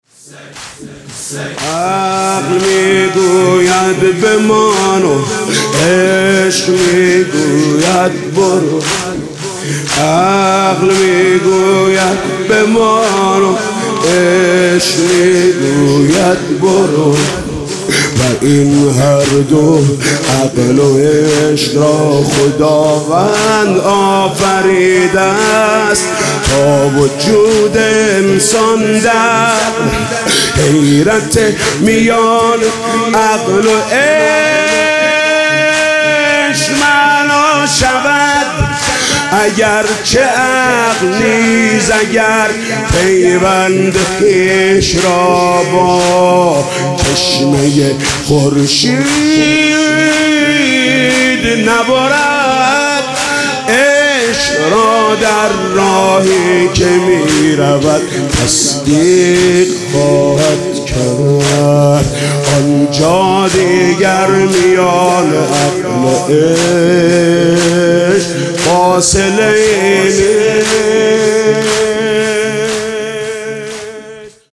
محرم 97 شب هشتم - کریمی - زمینه - قسمتی از کتاب فتح خون